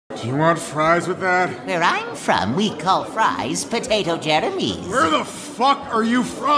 “Crimson Demon Clan” man hush sound effects free download